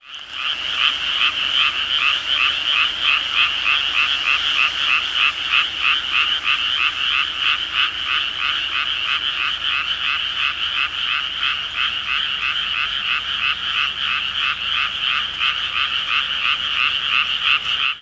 grenouilles coassaient à côté de moi...
grenouilles.mp3